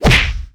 PunchHit3.wav